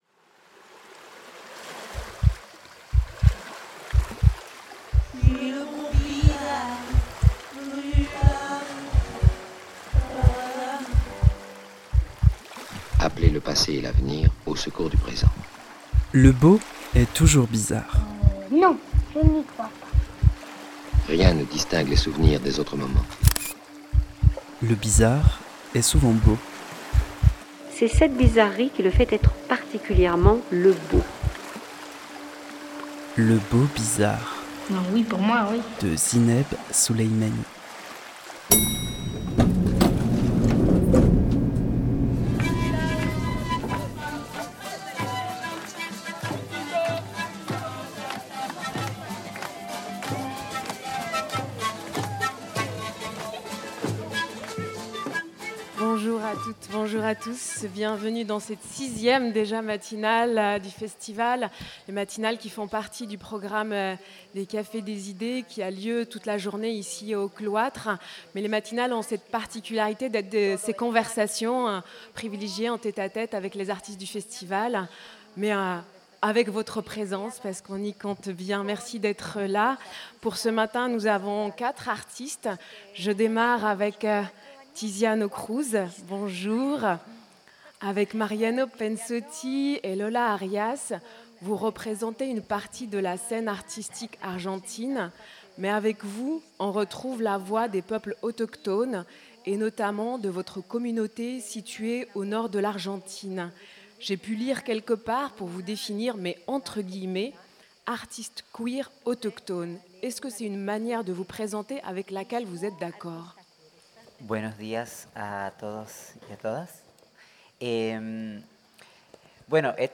dans le cadre de la matinale du Festival d’Avignon. Rendez-vous régulier du Café des idées avec les artistes qui font l’actualité du Festival